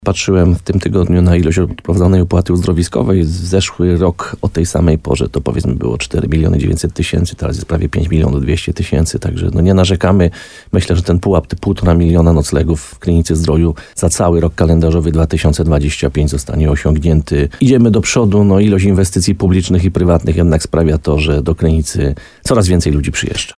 Ten rok pod kątem odwiedzin jest jeszcze lepszy niż poprzedni – tak o sezonie wakacyjnym w Krynicy-Zdroju mówi burmistrz tego samorządu Piotr Ryba. Gość programu Słowo za Słowo na antenie RDN Nowy Sącz oceniał sytuacje na półmetku letniego wypoczynku.